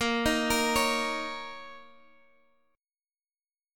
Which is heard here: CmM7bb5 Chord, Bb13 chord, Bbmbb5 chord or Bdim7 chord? Bbmbb5 chord